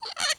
Cupboard Door